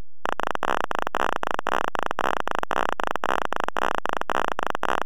inditózás 16-1.wav 2011-06-12 12:04 870K